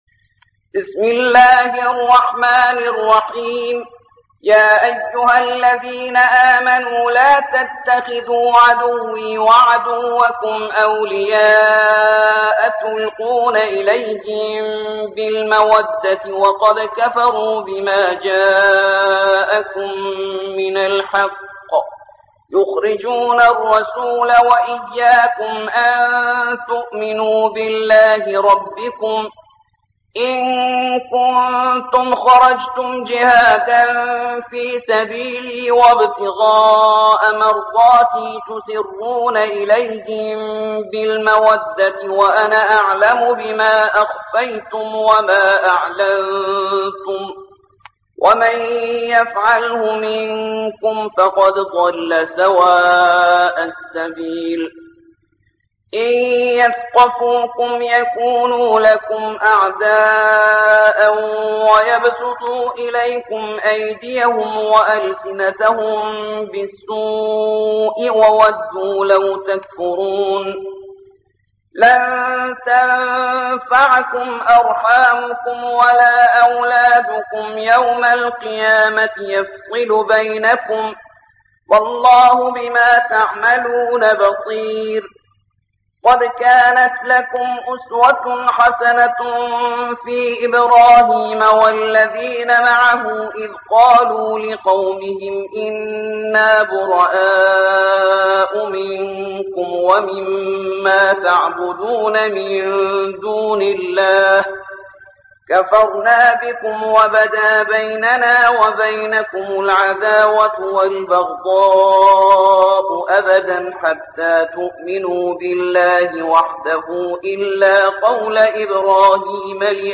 60. سورة الممتحنة / القارئ